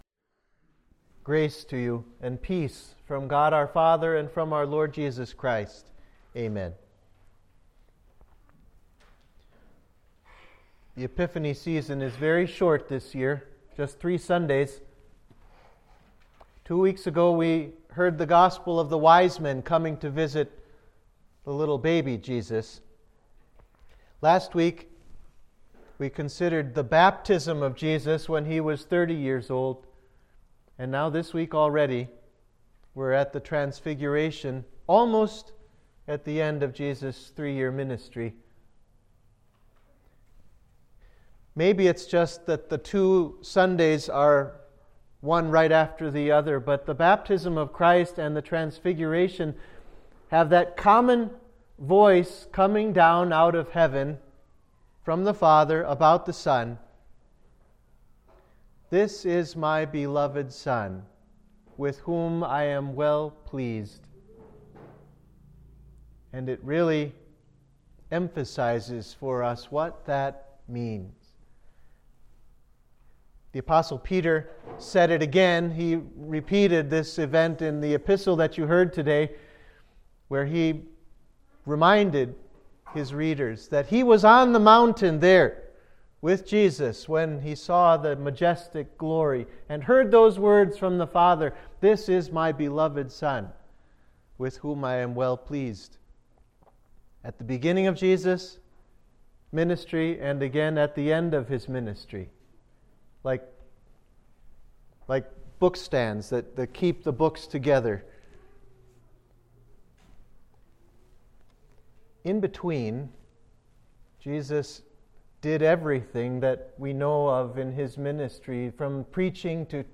Sermon for Transfiguration of Our Lord